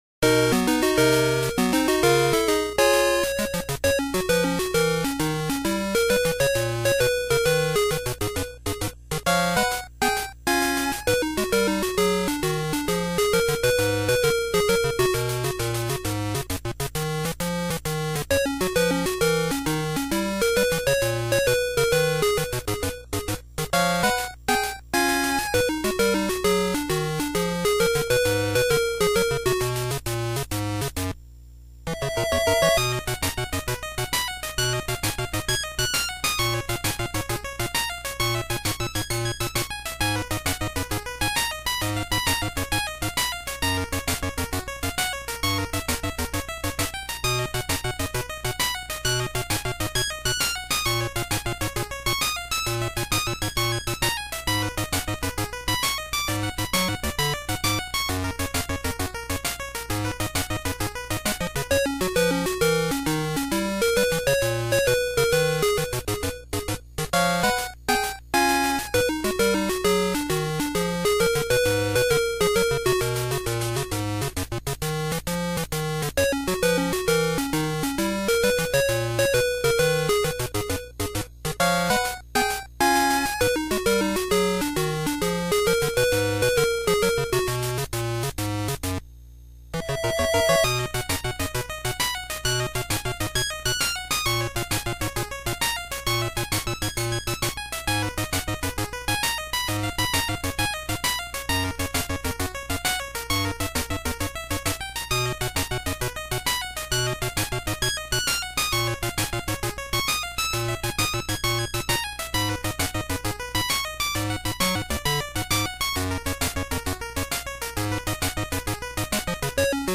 Música del tablero rojo de la banda sonora